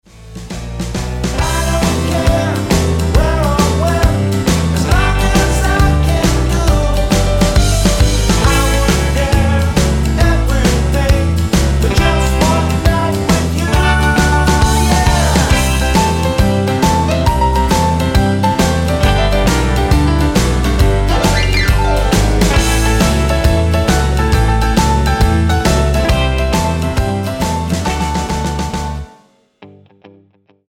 Tonart:G mit Chor